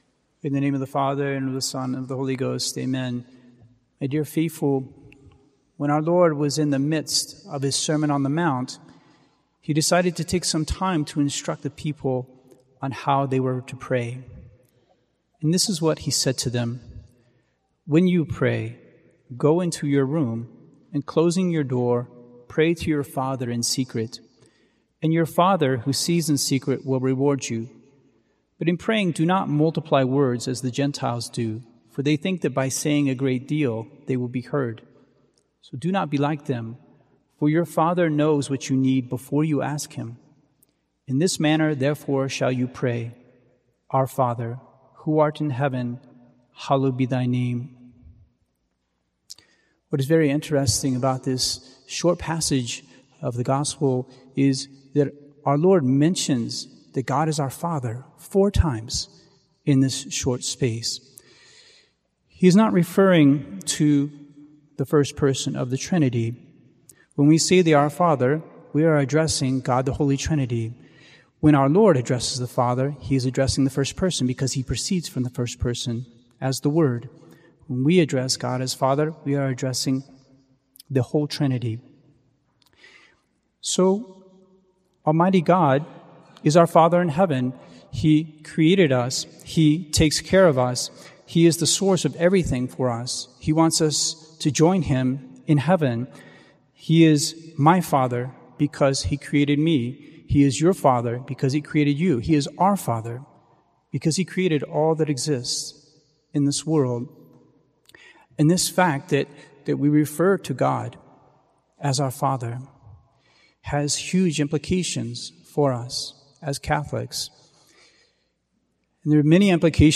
Our Lady and the Choice of Our State of Life, Sermon